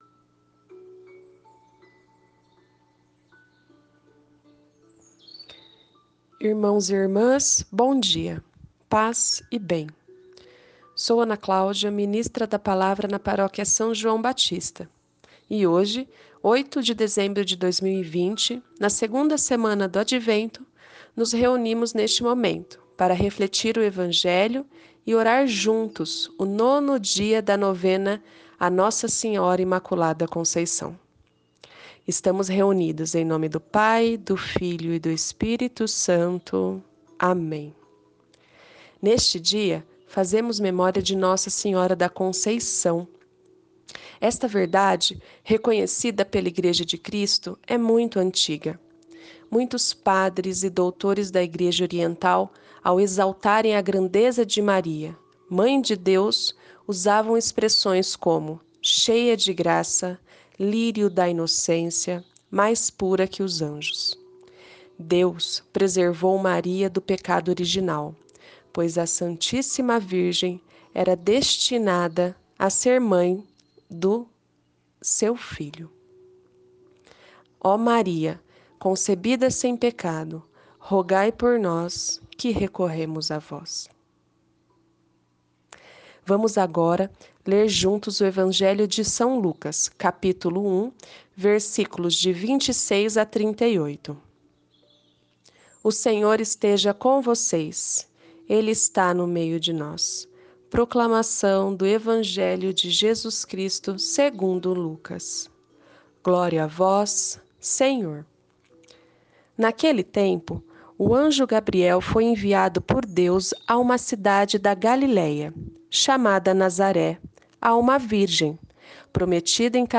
9ºDIA DA NOVENA DE NOSSA SENHORA DA CONCEIÇÃO E EVANGELHO DE HOJE